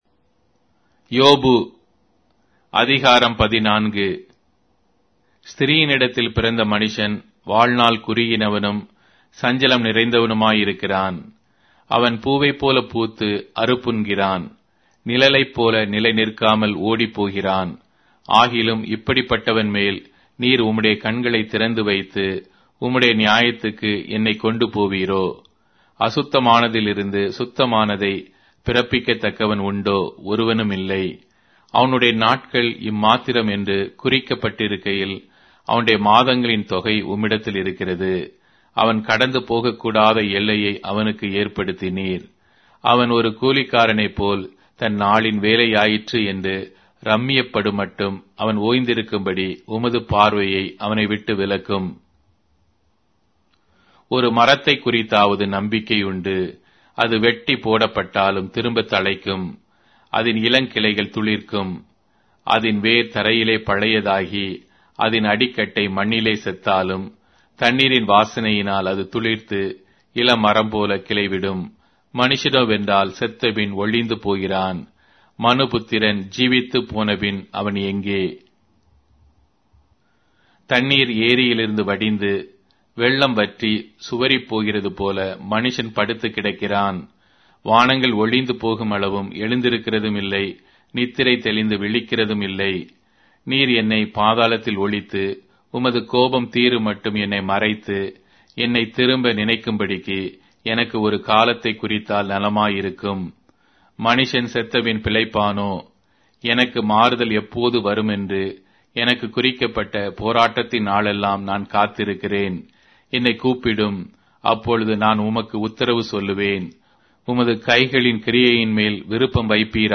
Tamil Audio Bible - Job 6 in Tov bible version